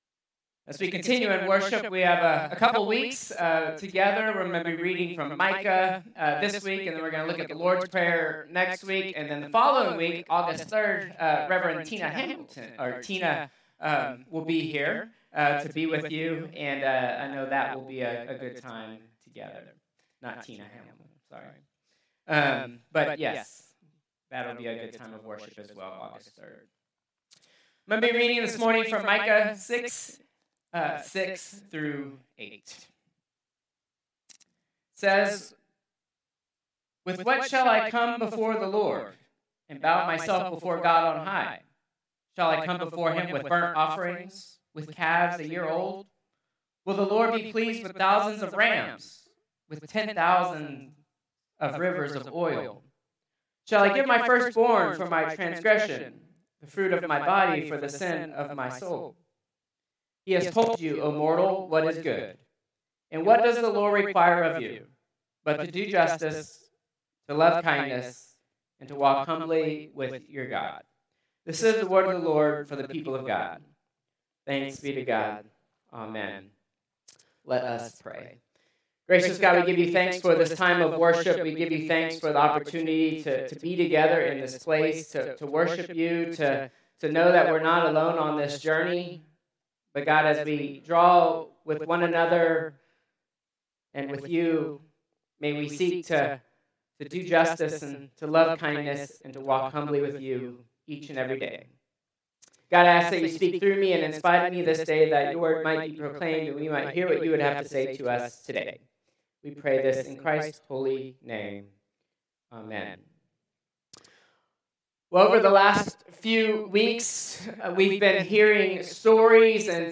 Contemporary Service 7/20/2025